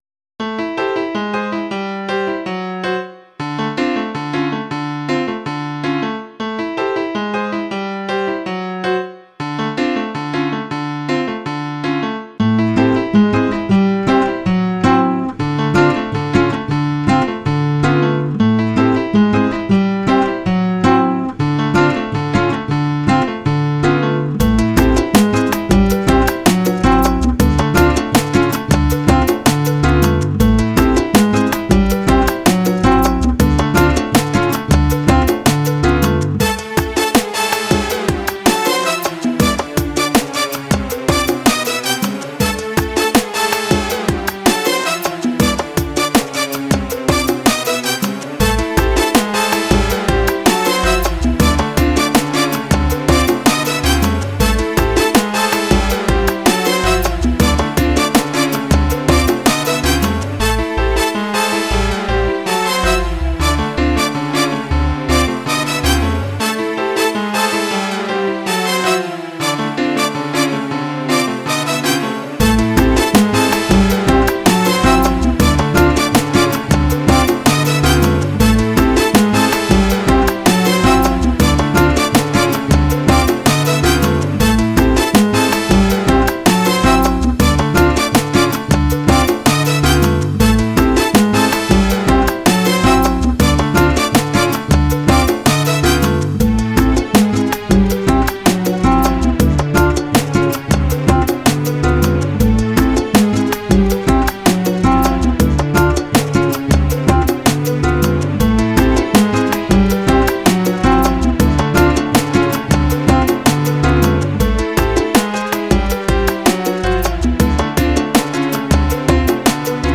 House
Musica  per Dj - Discoteca